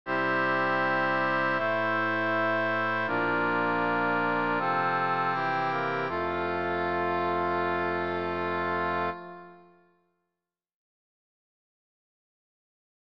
１．F-durへの転調
C-dur>F-durの転調ではもちろん上記以外にもいろいろな方法があります。